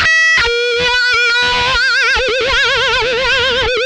MANIC WAH 19.wav